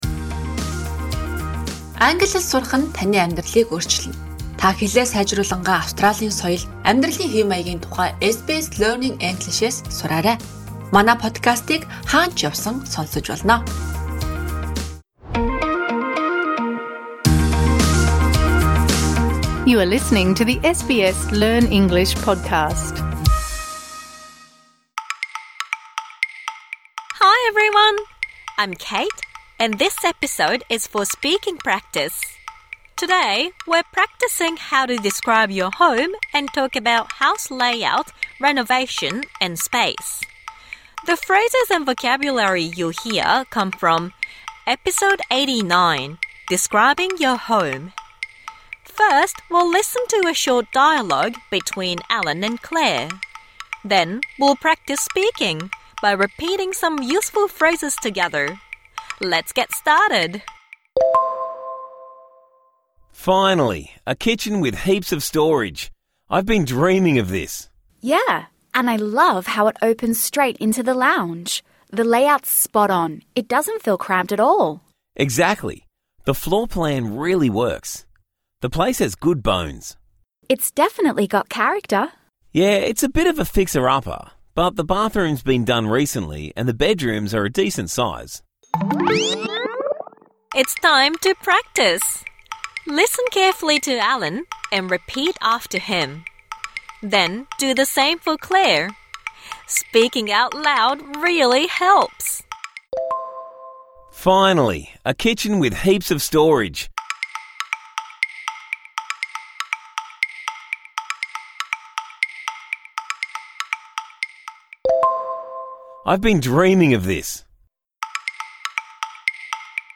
Learn the meanings of the phrases used in this dialogue: #89 Describing your home (Med) SBS English 14:25 English SBS Learn English will help you speak, understand and connect in Australia - view all episodes.